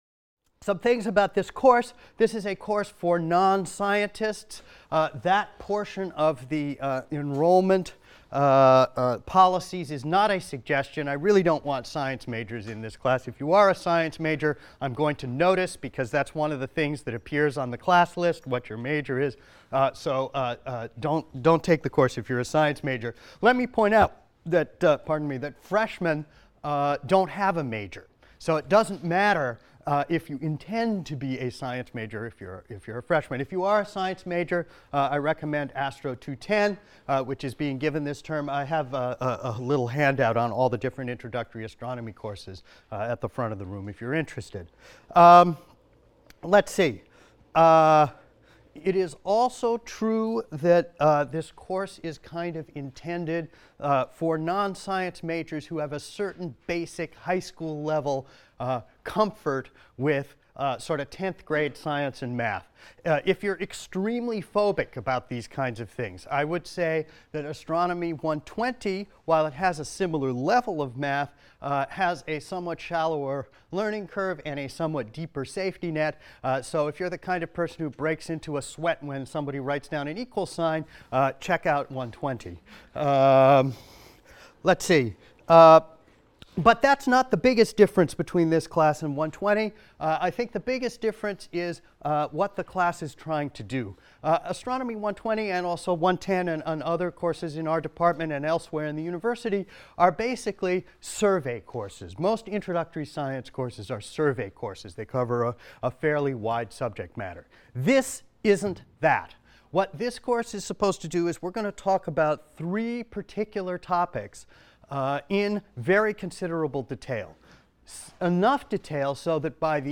ASTR 160 - Lecture 1 - Introduction | Open Yale Courses